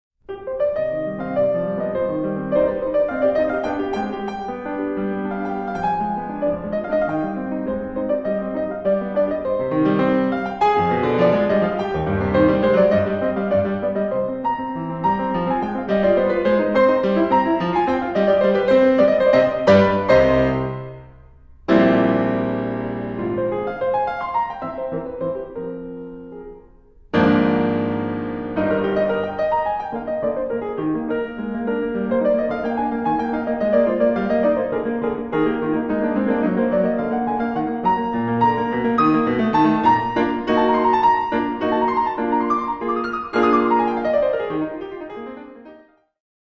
Melba Hall
Classical, Keyboard